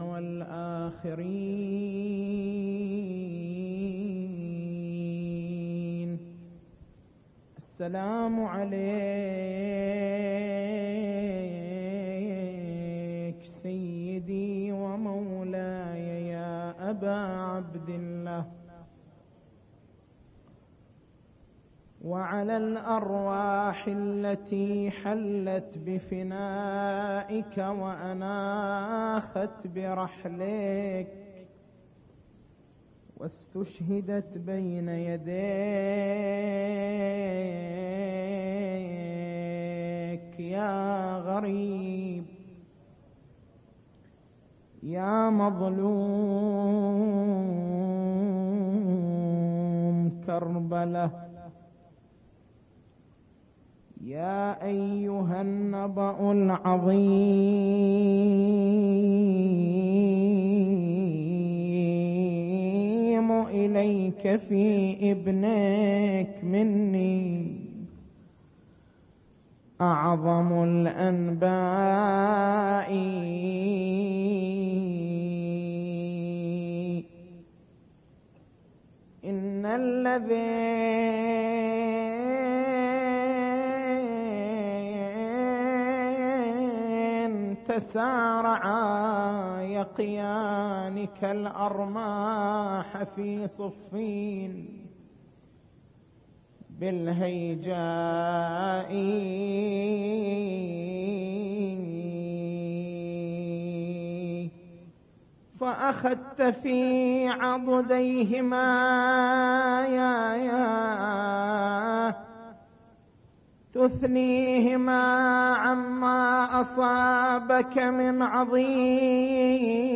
مكتبة المحاضرات